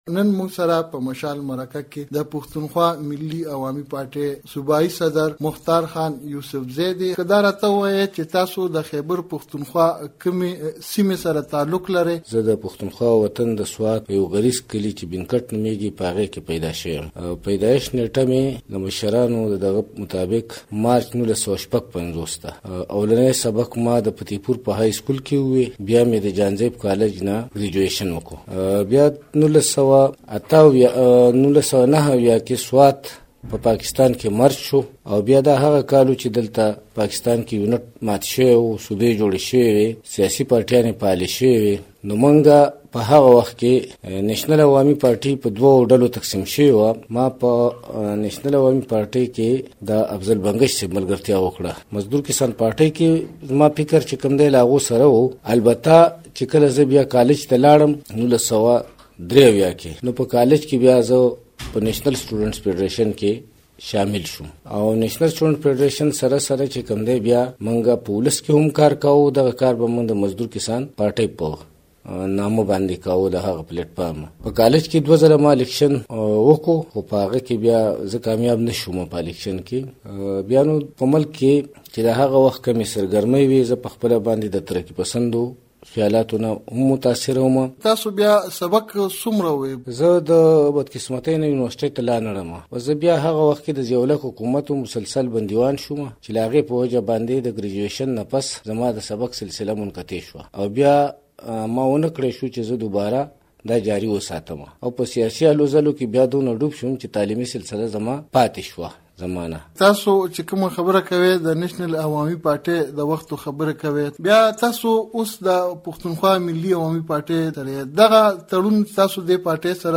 د مشال مرکه